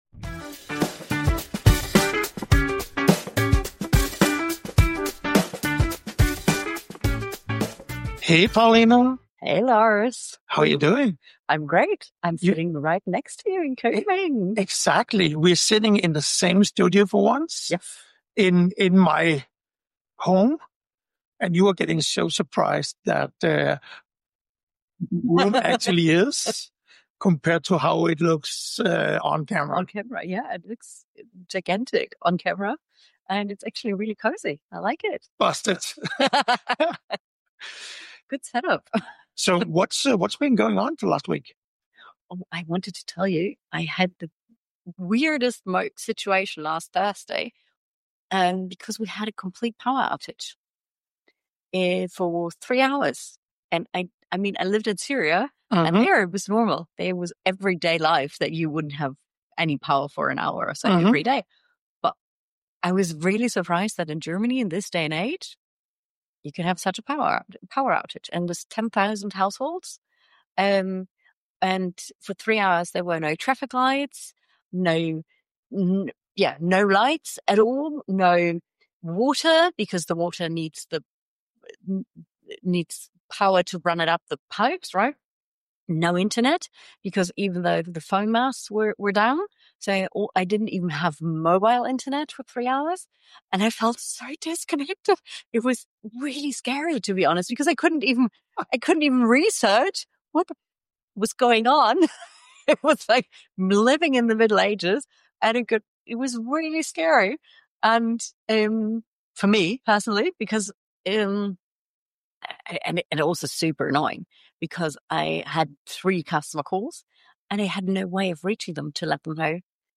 It is an interactive format, where we discuss live cases anonymously and give tips and tricks both for companies and individuals experiencing these situations.